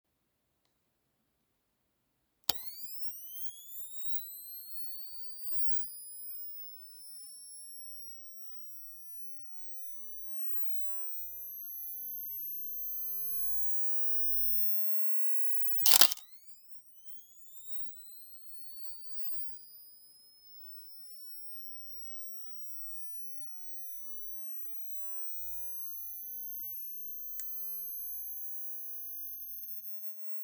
Film Camera Flash Sound